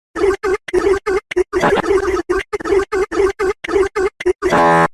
Goofy Ahh Brain Fart Meme